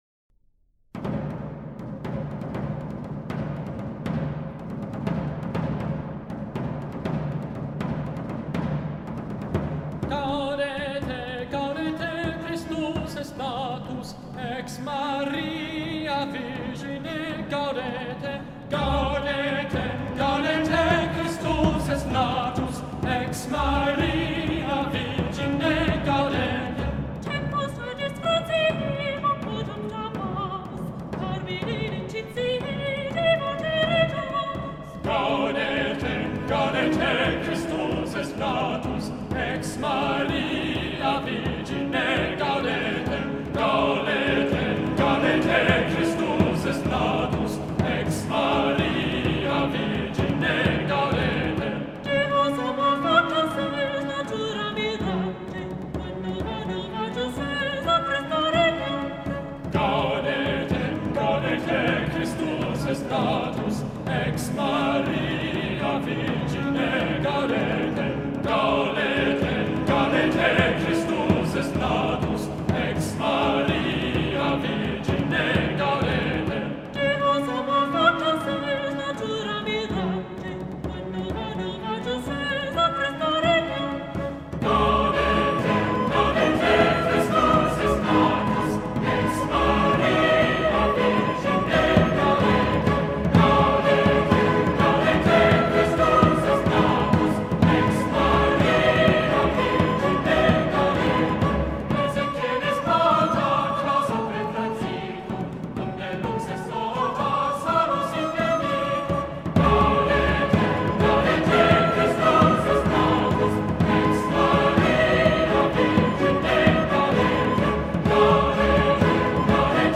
medieval-carol-gaudete-choir-of-clare-college-cambridge-london-cello-orchestra.mp3